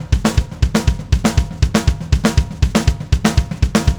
Power Pop Punk Drums 03b.wav